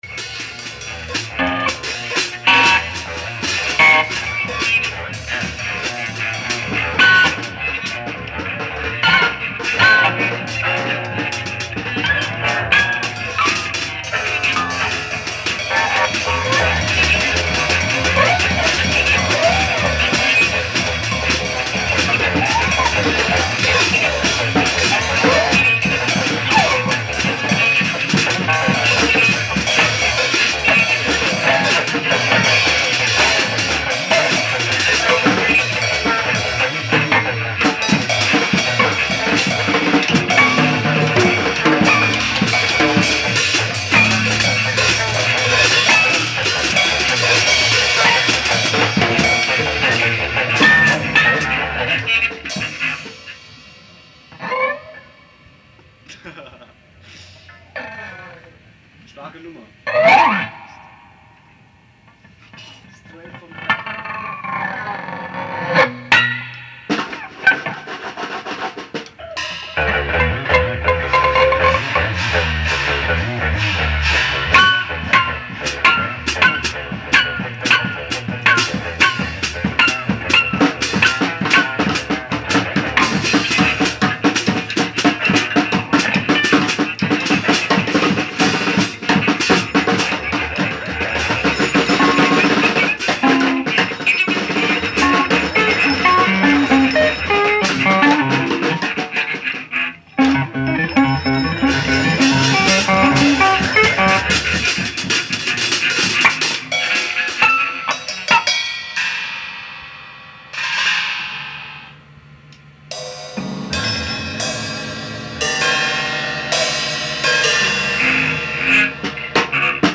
Session
guitar
guitar/bass
drums/voice
cello